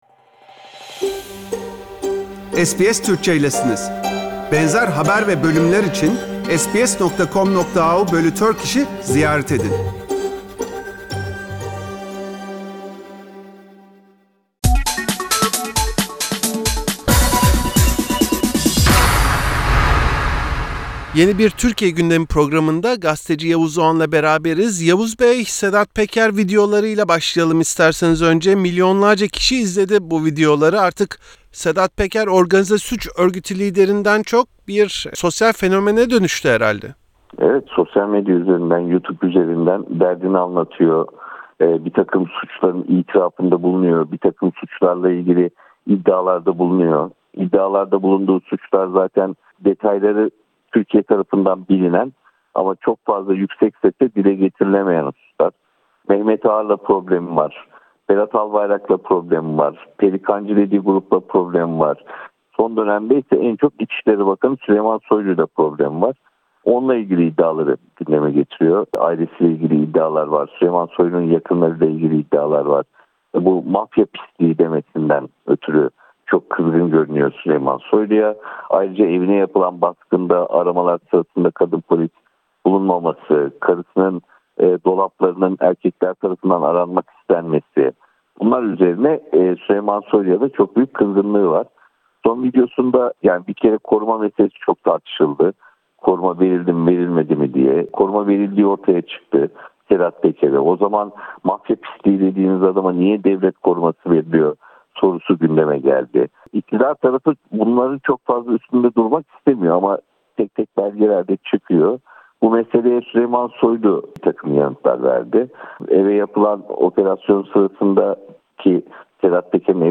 Gazeteci Yavuz Oğhan SBS Türkçe için Sedat Peker’in videolarını ve toplum için gösterdiklerini değerlendirdi.